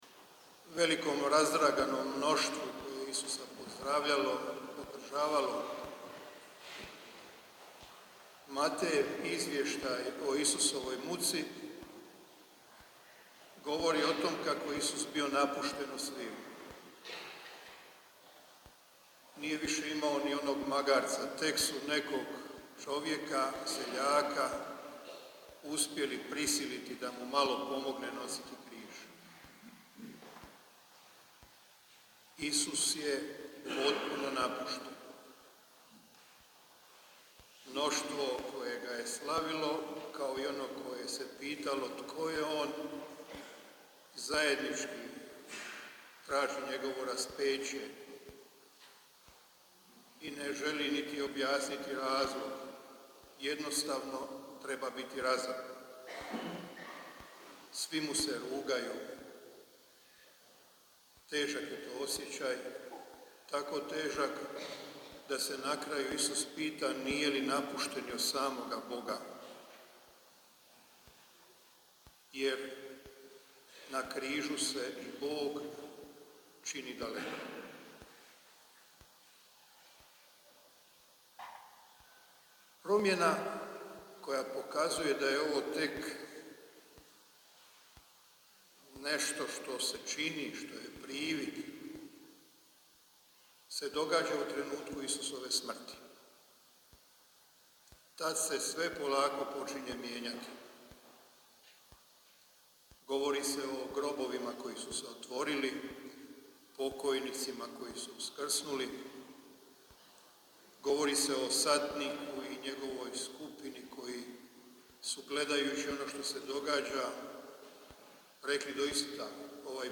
prop-cvjetnica.mp3